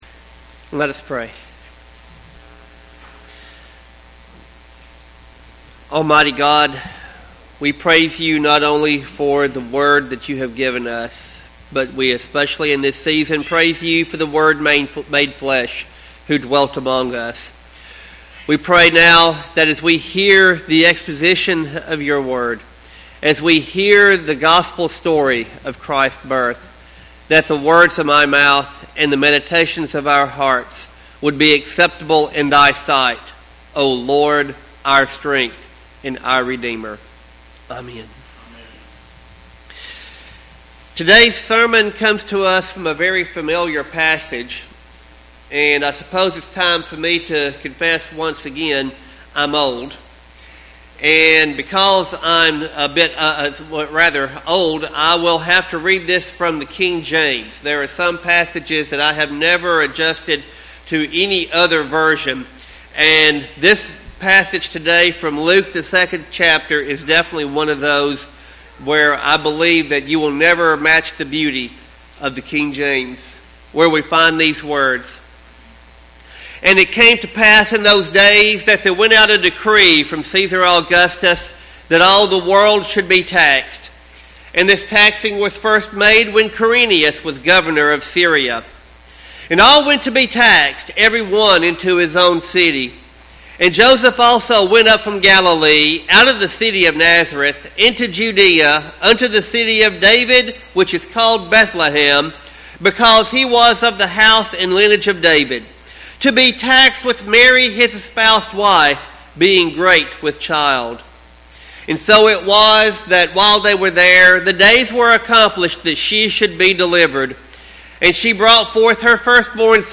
Sermon text: Luke 2:1-20.